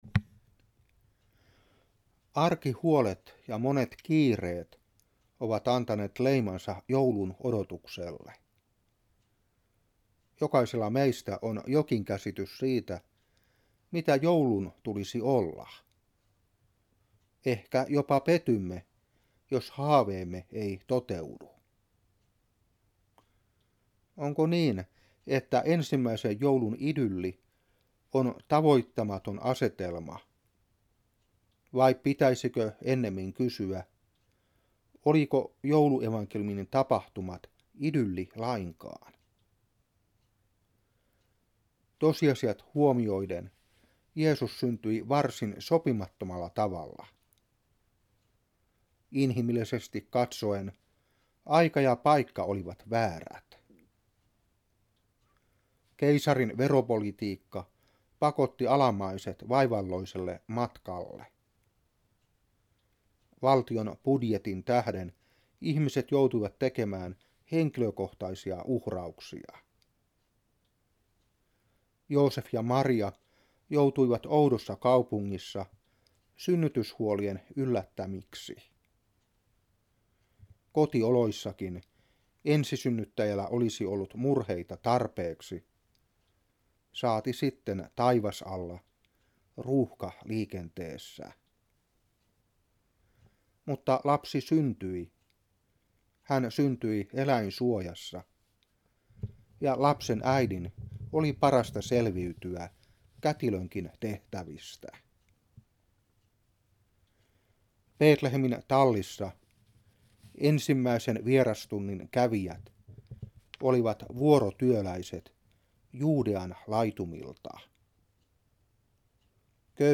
Saarna 1990-12.